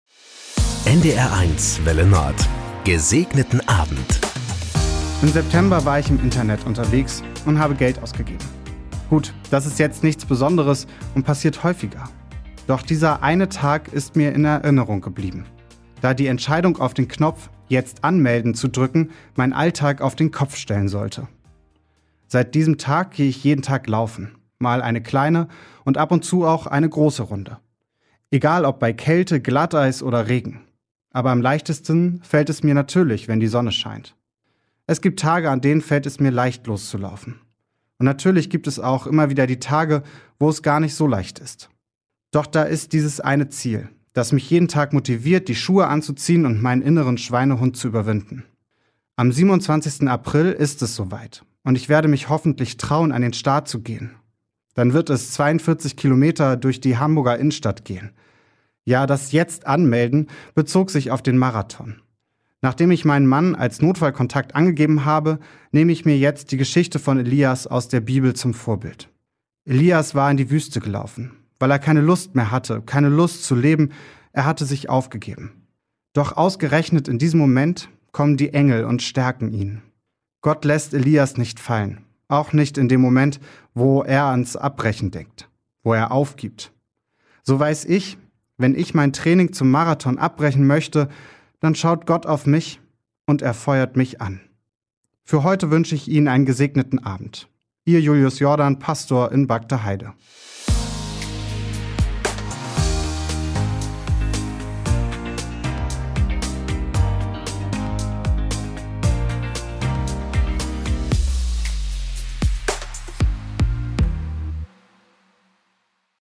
Das gute Wort zum Feierabend auf NDR 1 Welle Nord mit den Wünschen für einen "Gesegneten Abend".
Täglich um 19.04 Uhr begleiten wir Sie mit einer Andacht in den Abend - ermutigend, persönlich, aktuell, politisch, tröstend.